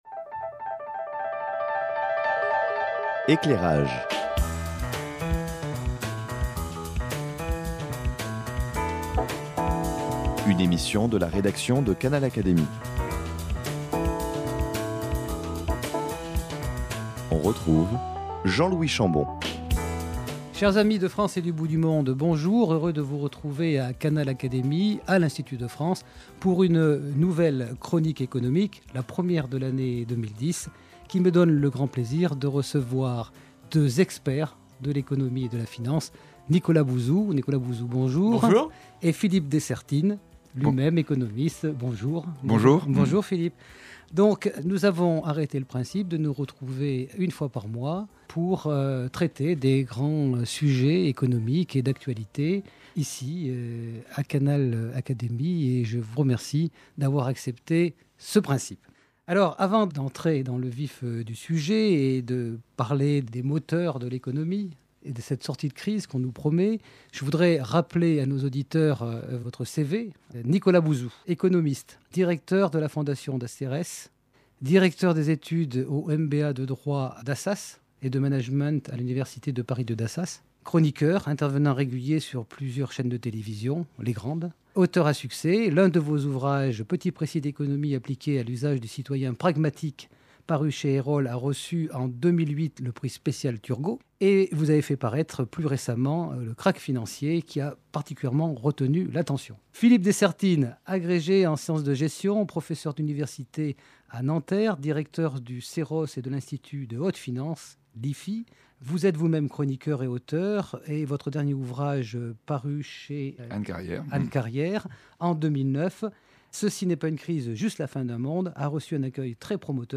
Nicolas Bouzou et Philippe Dessertine, auteurs et chroniqueurs sur les médias nationaux, échangent et apportent leur réflexion sur les grands sujets qui promettent d’alimenter l’actualité économique et sociale en 2010, année que chacun espère comme celle de la sortie de crise.